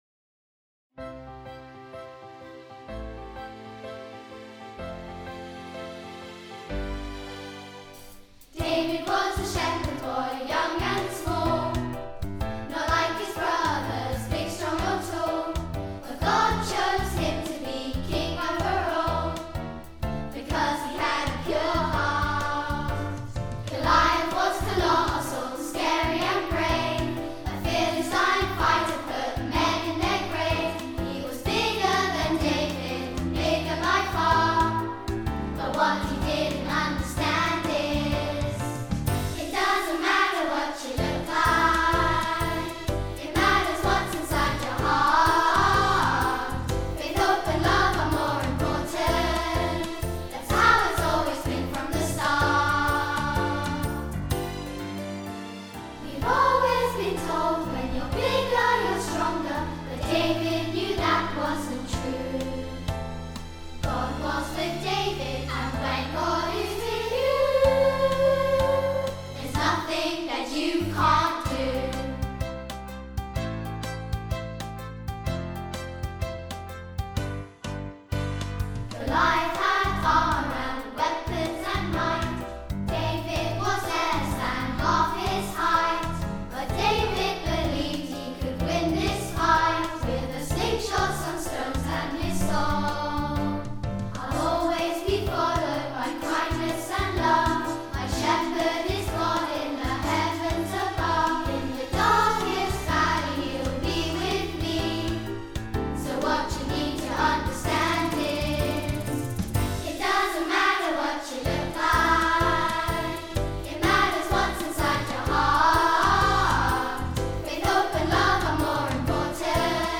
Harmony